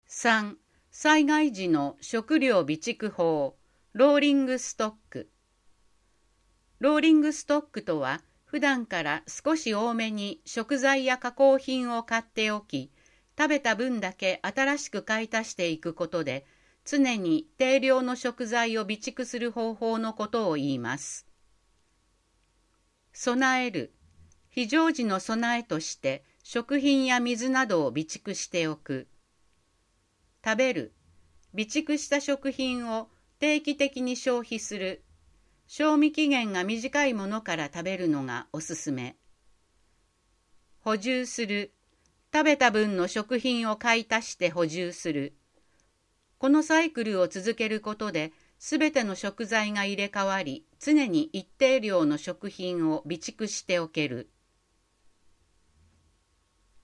豊中市総合ハザードマップ音訳版(1)1.災害を知る～3.大雨時のとるべき行動